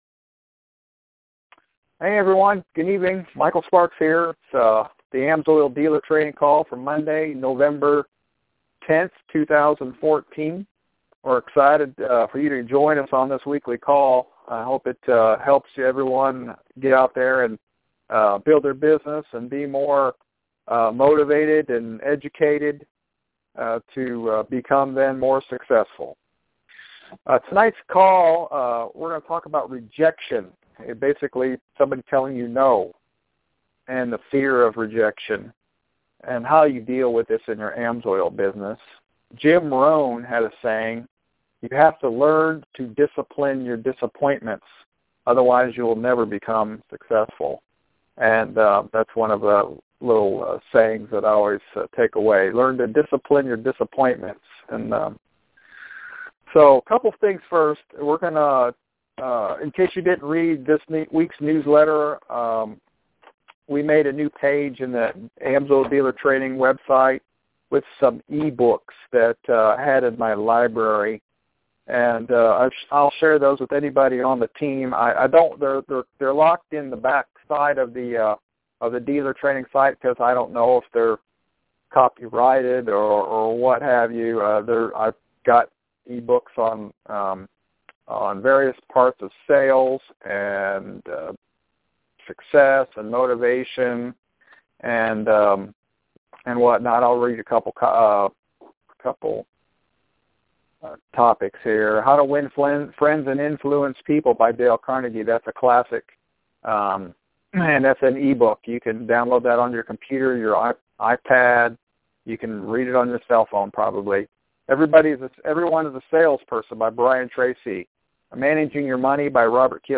AMSOIL Dealer training call, we discuss rejection and how to deal with prospects that say No.